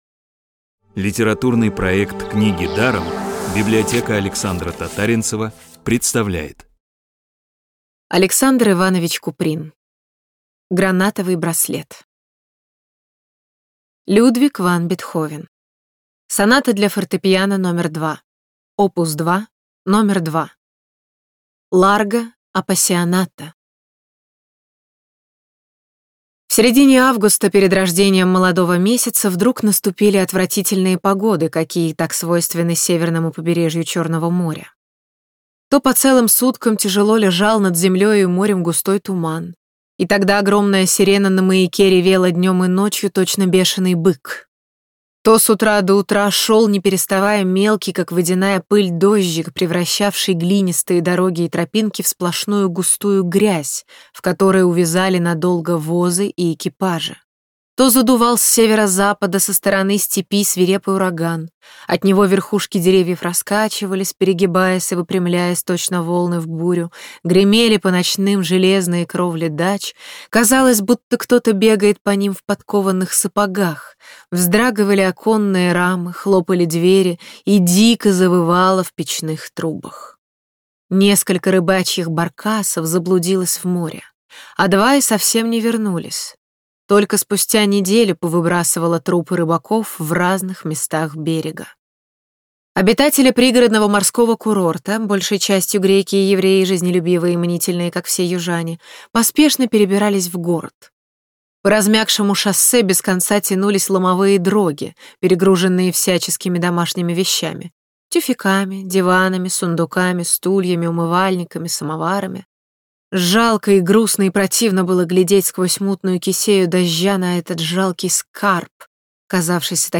Аудиокнига бесплатно «Гранатовый браслет» от Рексквер.
Аудиокниги онлайн – слушайте «Гранатовый браслет» в профессиональной озвучке и с качественным звуком. А. И. Куприн - Гранатовый браслет.